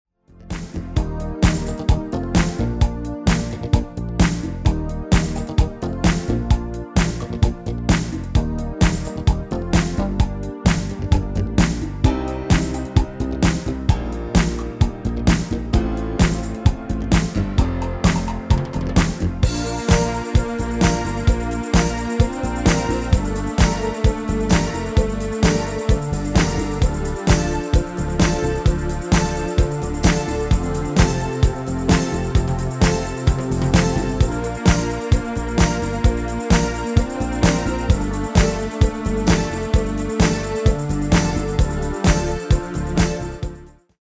Genre: Disco
Toonsoort: Bb
Demo's zijn eigen opnames van onze digitale arrangementen.